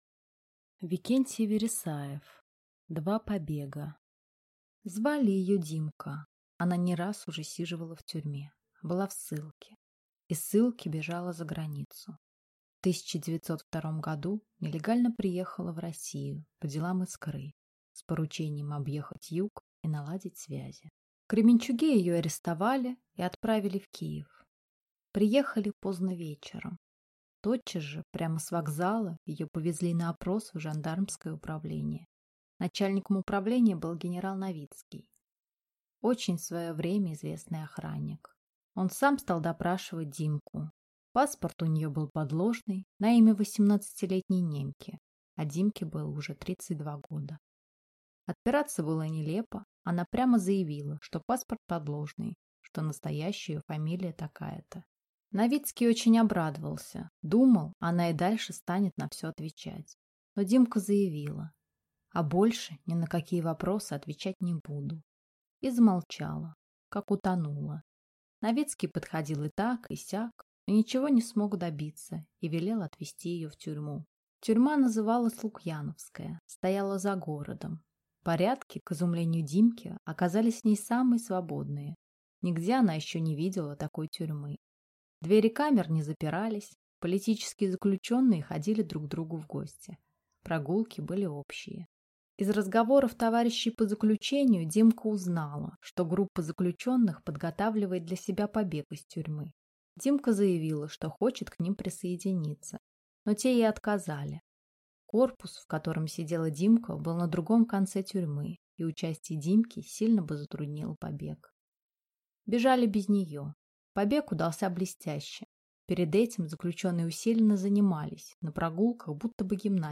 Аудиокнига Два побега | Библиотека аудиокниг